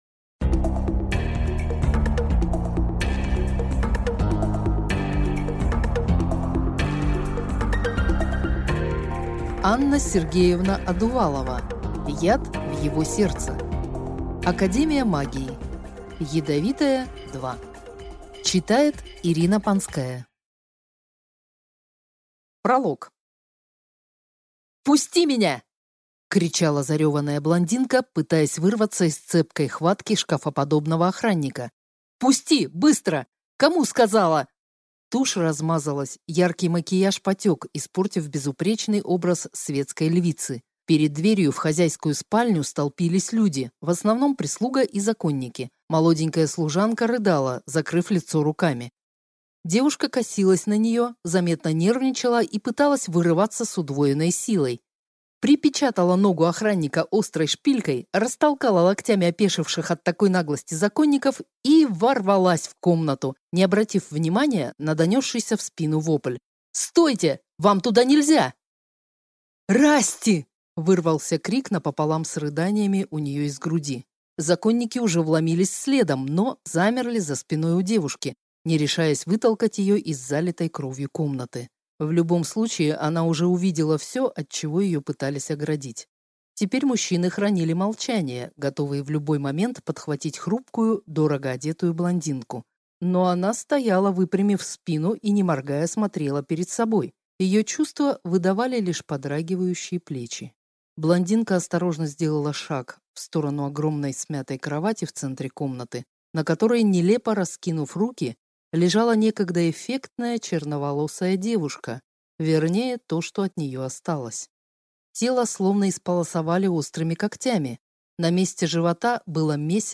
Аудиокнига Яд в его сердце | Библиотека аудиокниг